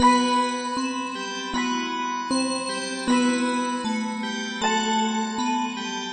Tag: 156 bpm Trap Loops Bells Loops 1.03 MB wav Key : Unknown